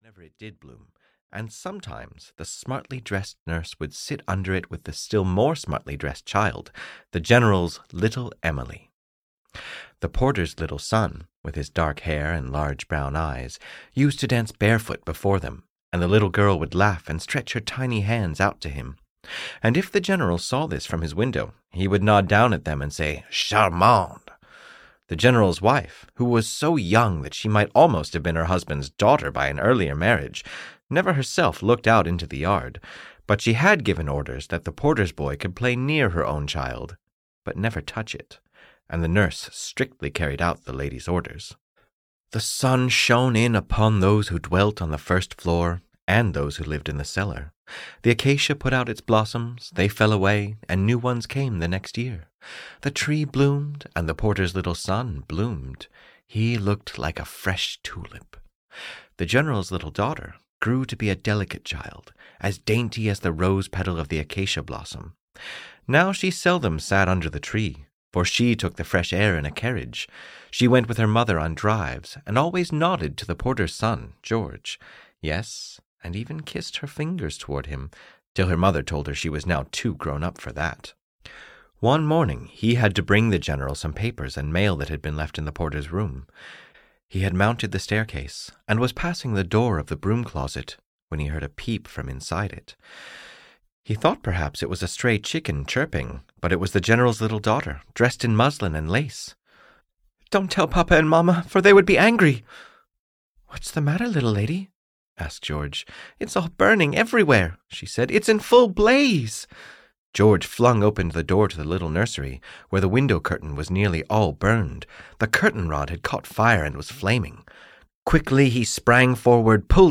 The Porter's Son (EN) audiokniha
Ukázka z knihy